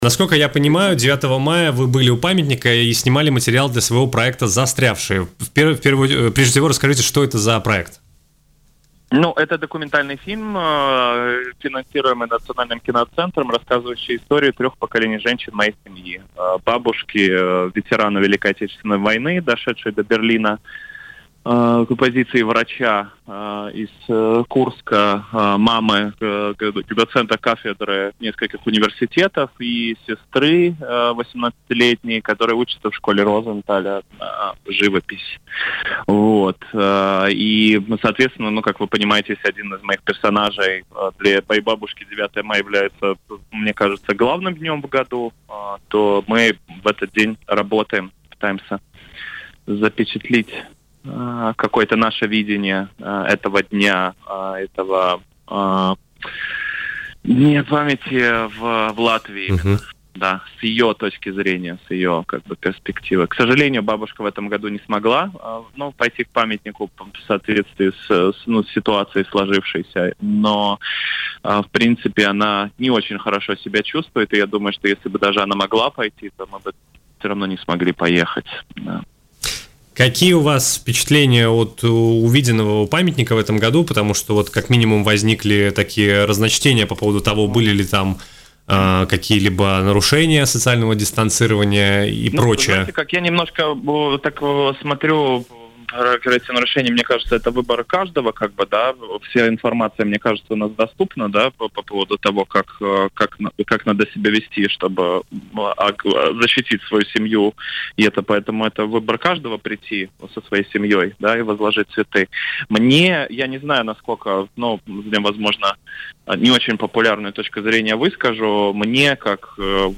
в эфире радио Baltkom поделился своими впечатлениями от празднования 9 мая в Латвии в условиях чрезвычайной ситуации.